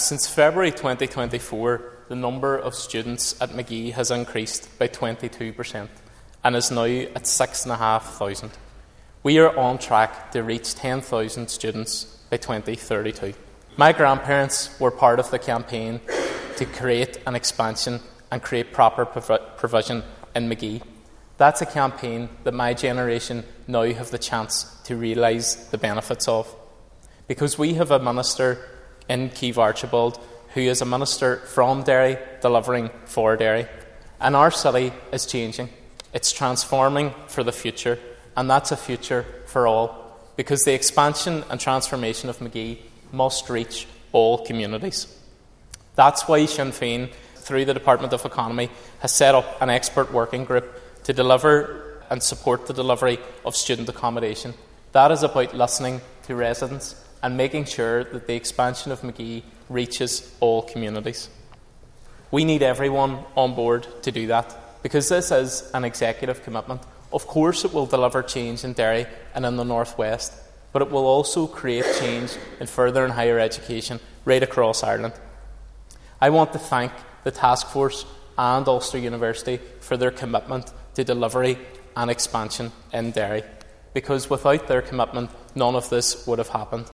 Foyle MLA Padraig Delargey told the Assembly that expanding Magee has between a priority for Sinn Fein Ministers, and their commitment will continue.